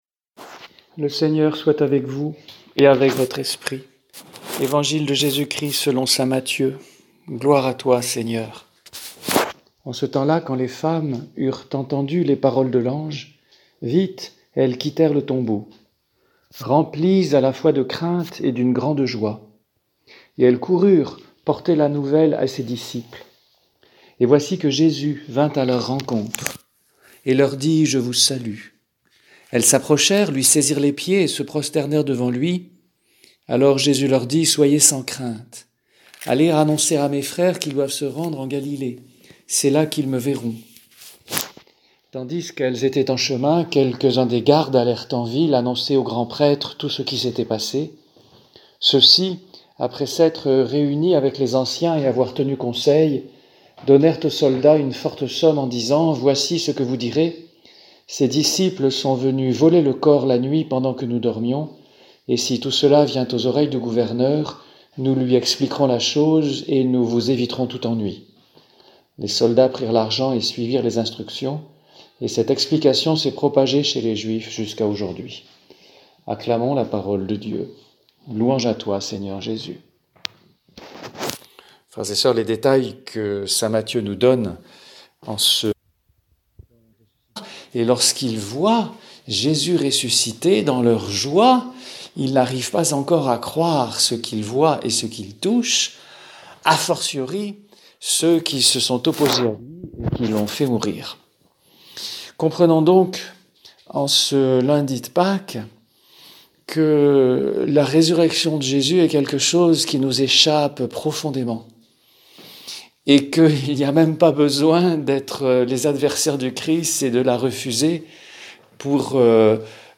Homélie prononcée le 13 avril 2020
Homélie